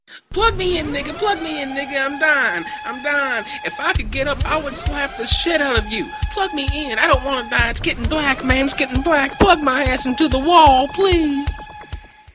Low Battery Alert